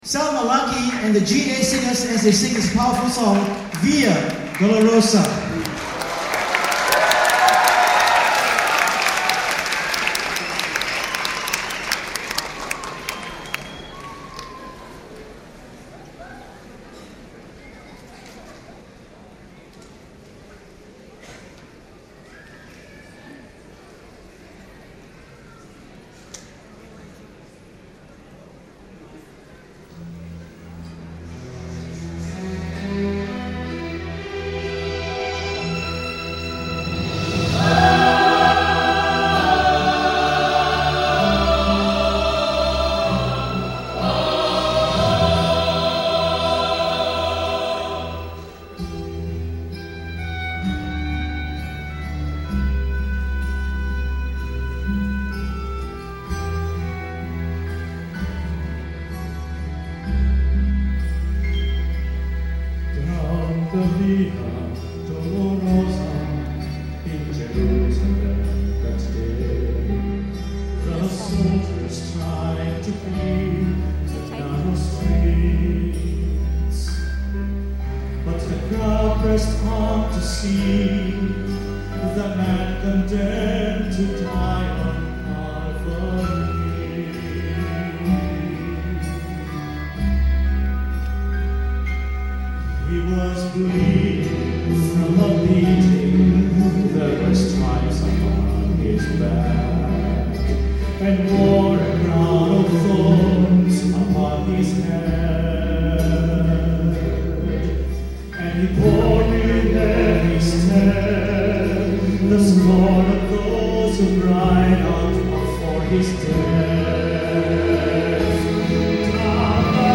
I took the opportunity to test out the in-ear binaurals I got from The Sound Professionals and borrowed an old MiniDisc player.
These songs are best heard on headphones to hear the direction of chatter, laughter and so on.